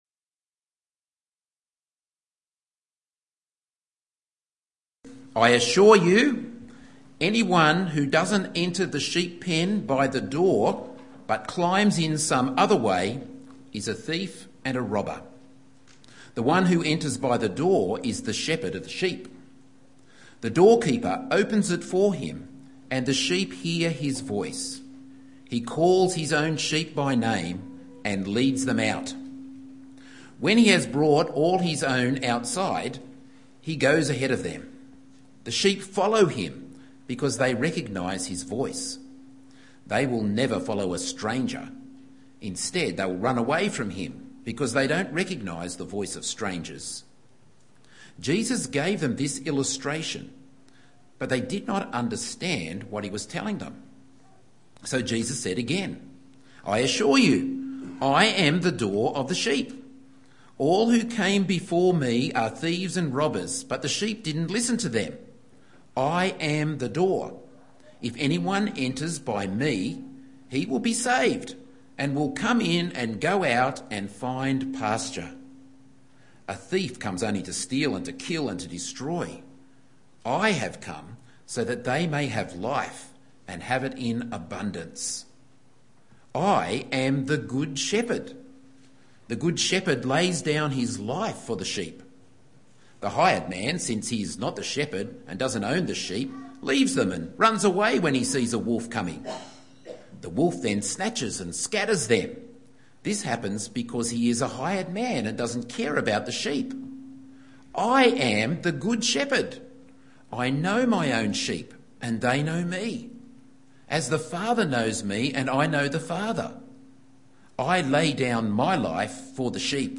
Morning Church - Evening Church